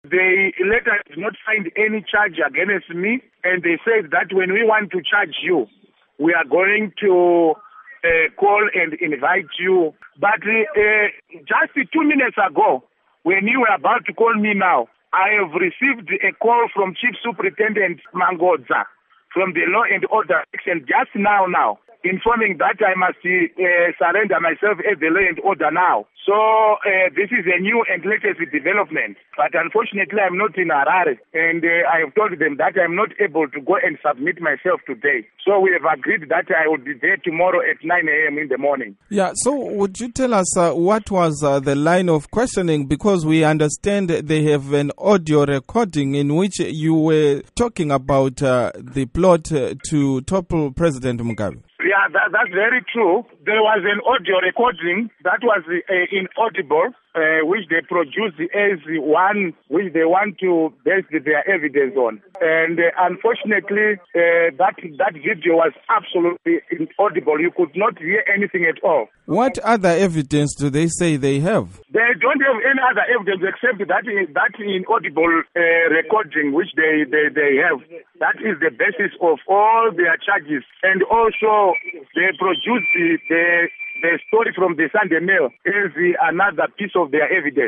Interview With Job Sikhala on His Arrest